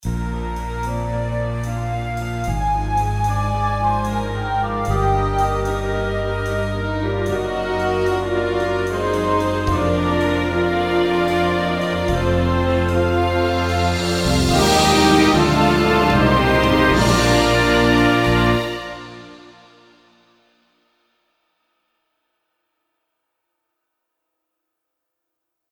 Orquestra